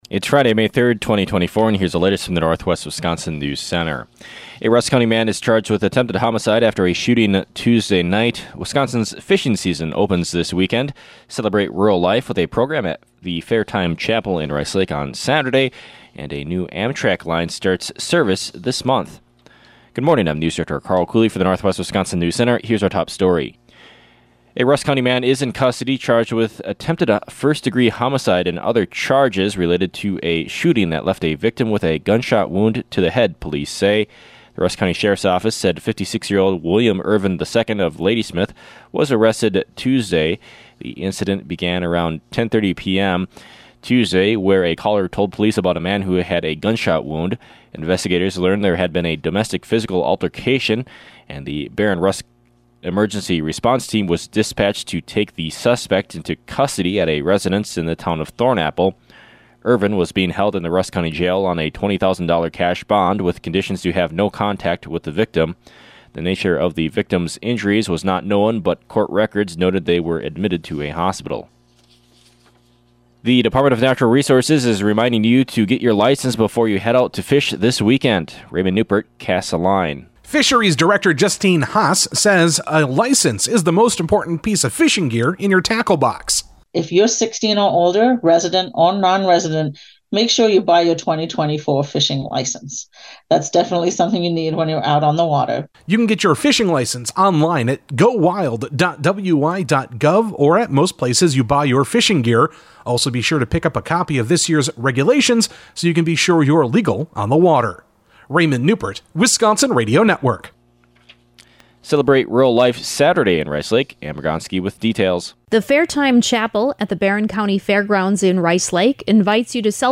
AM NEWSCAST – Friday, May 3, 2024 | Northwest Builders, Inc.